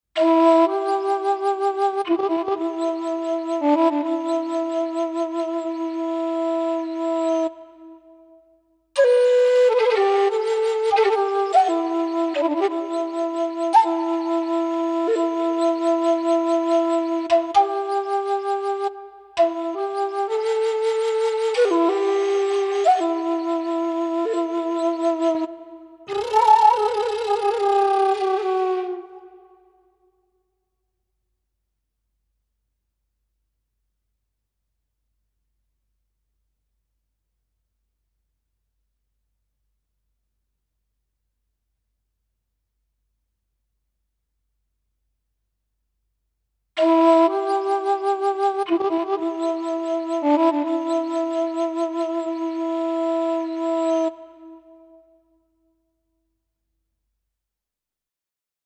Largo [0-10] suspense - flute - - -